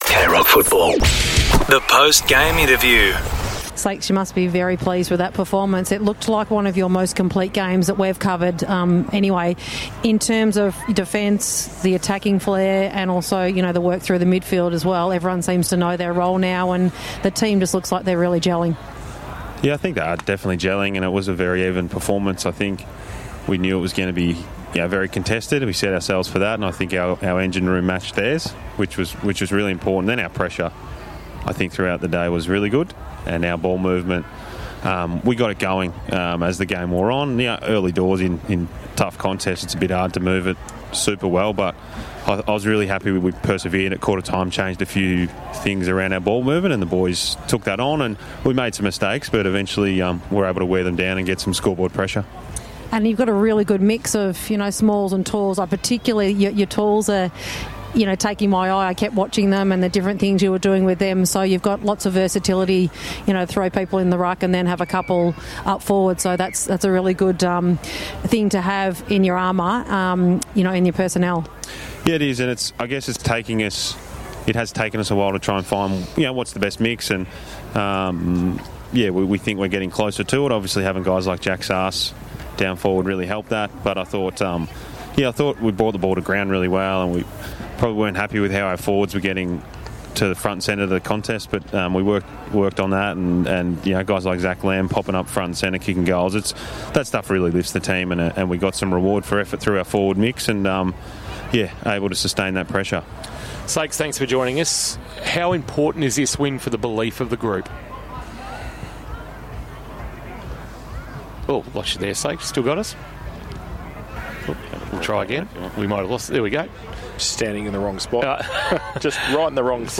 2025 - GFNL - Round 10 - Leopold vs. Bell Park: Post-match interview